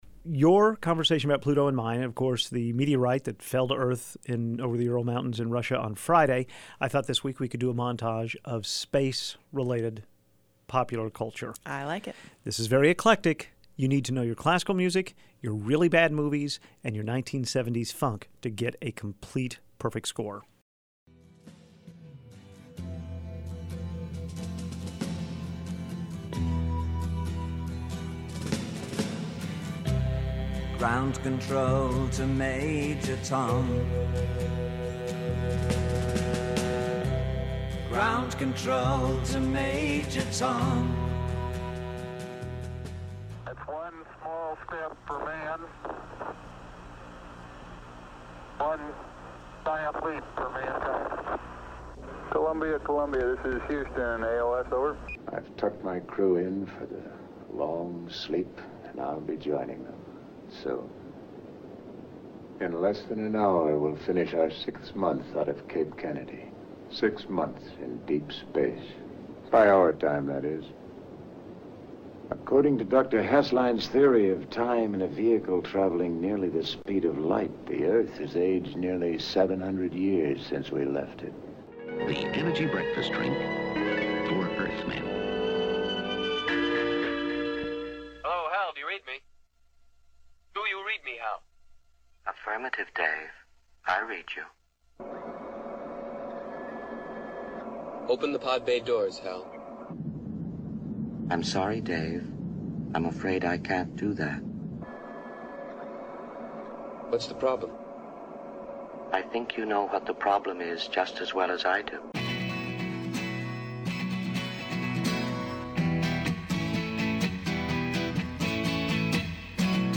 Here is our out-of-this-world list of material that made up our space montage this morning.